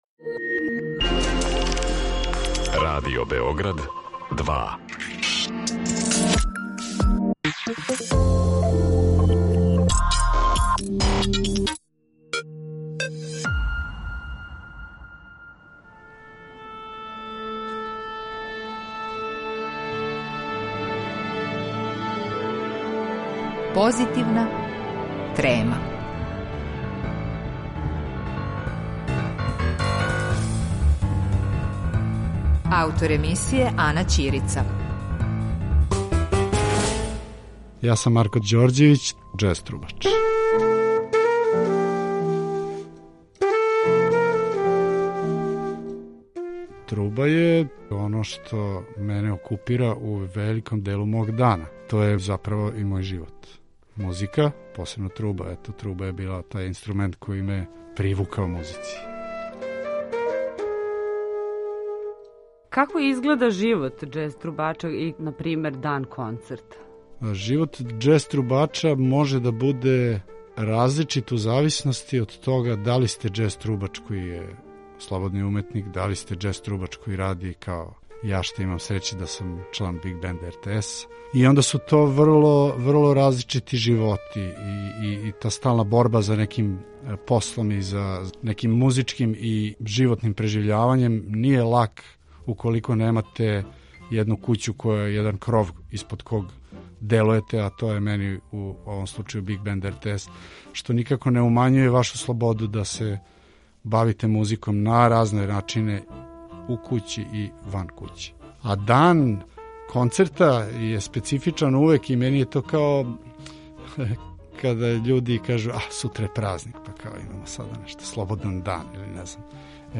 Џез трубач